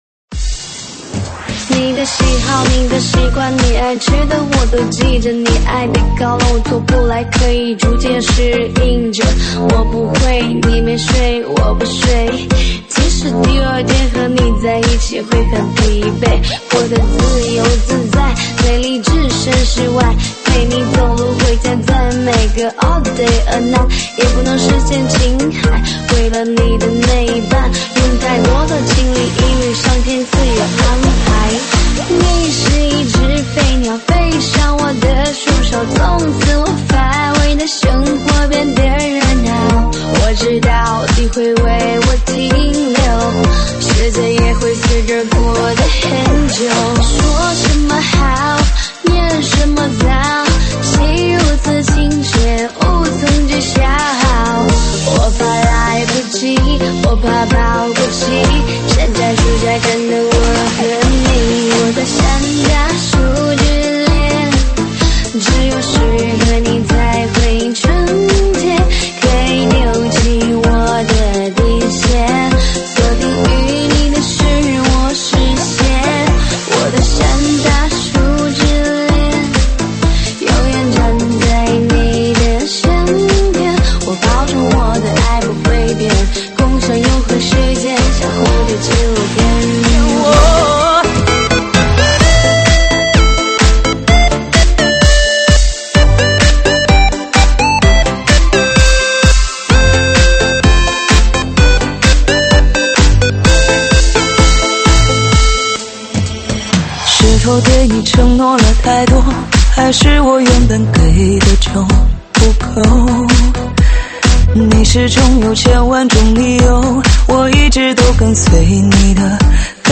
舞曲类别：中文Club